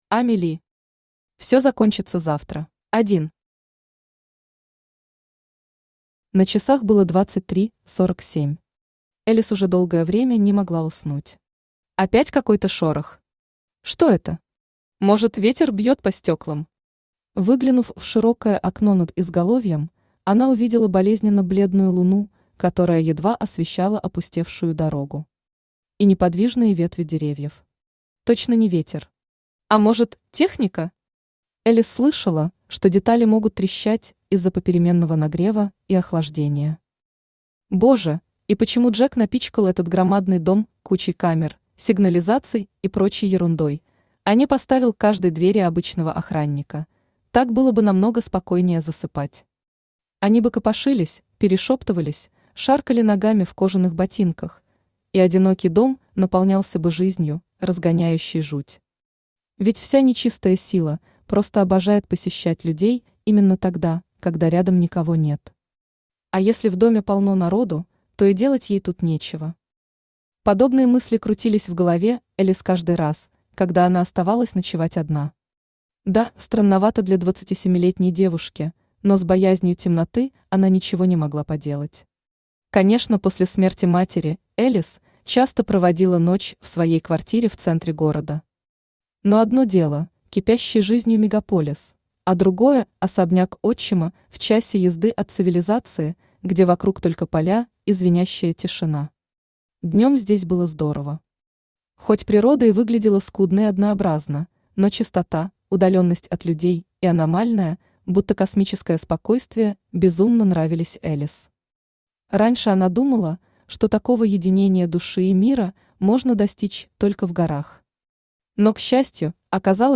Аудиокнига Все закончится завтра | Библиотека аудиокниг
Aудиокнига Все закончится завтра Автор Ами Ли Читает аудиокнигу Авточтец ЛитРес.